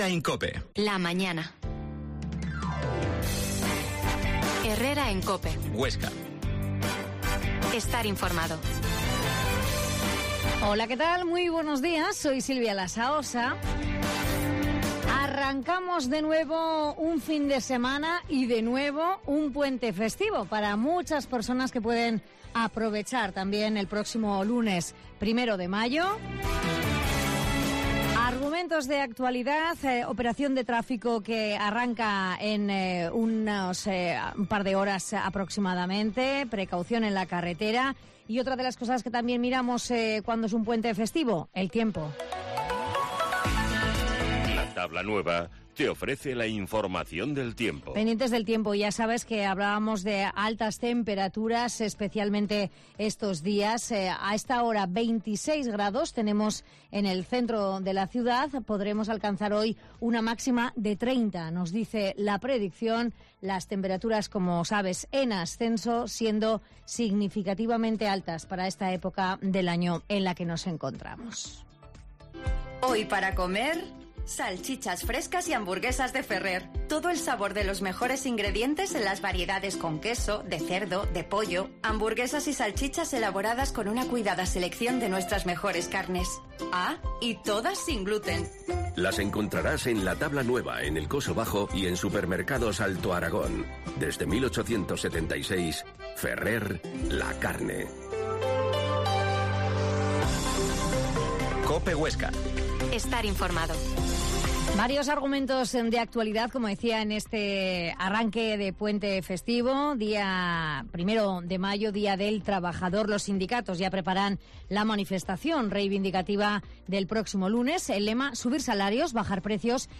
Herrera en COPE Huesca 12.50h Entrevista a la portavoz de la coord.